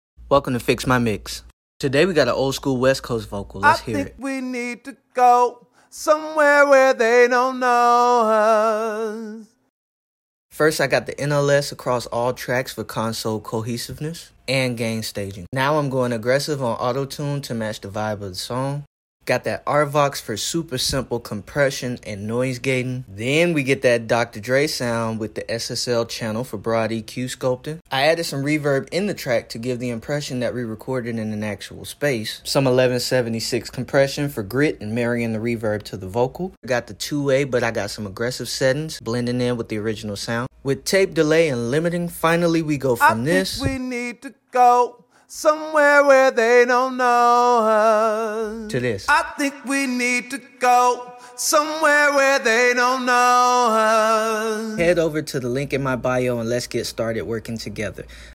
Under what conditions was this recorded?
Can I take a vocal recorded from home and make it sound like it was recorded in an old school LA studio 🤔 😎 Maybe.